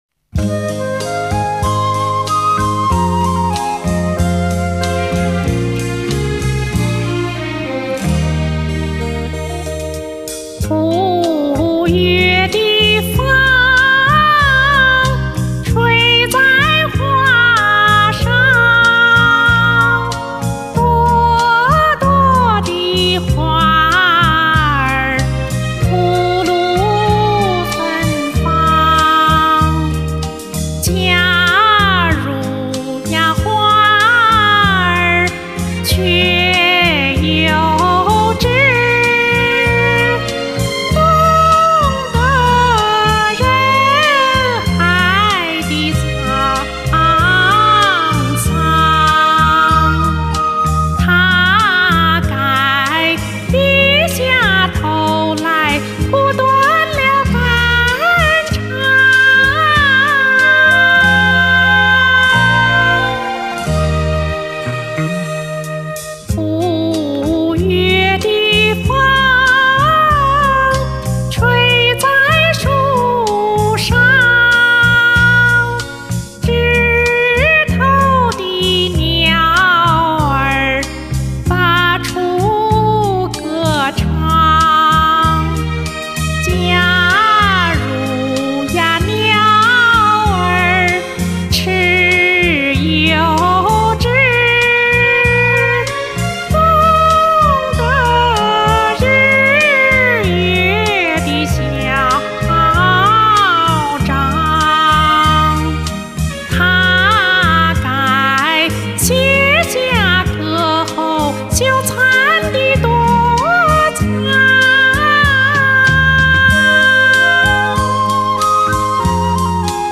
有年代味，余音袅袅的感觉，好听